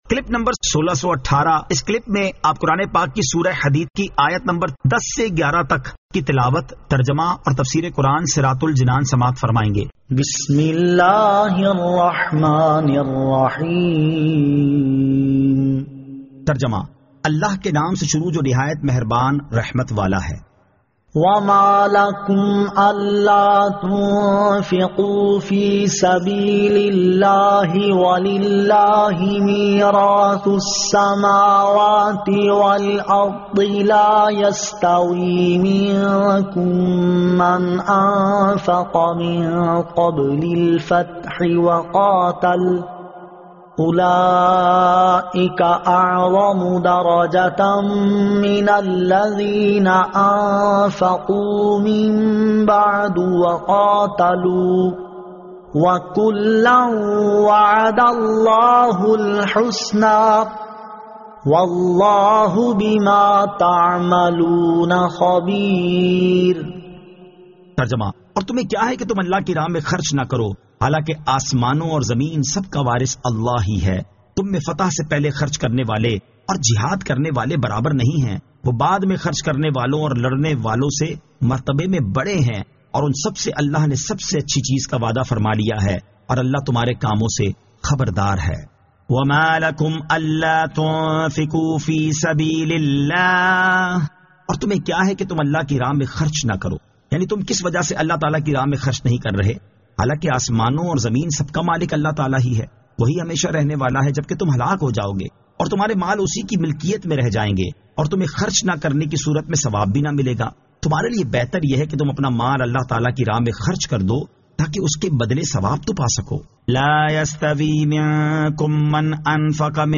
Surah Al-Hadid 10 To 11 Tilawat , Tarjama , Tafseer